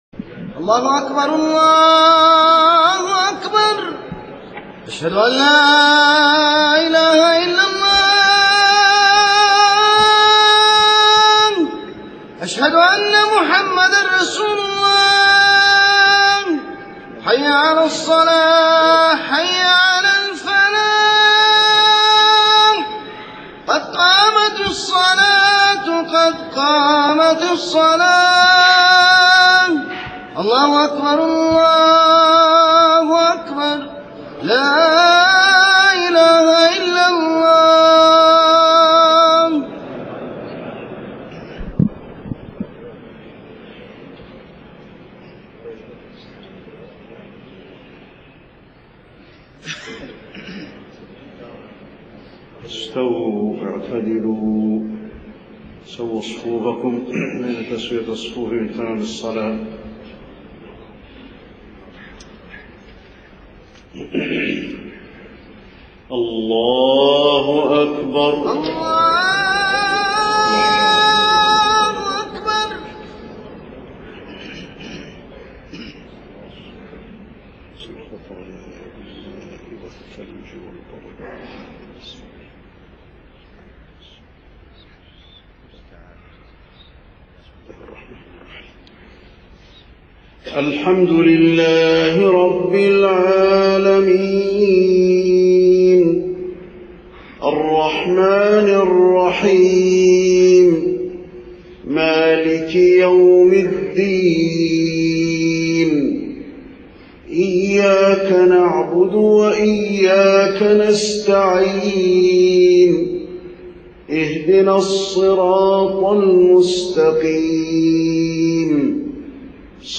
صلاة العشاء 5 صفر 1430هـ خواتيم سورة يونس 104-109 > 1430 🕌 > الفروض - تلاوات الحرمين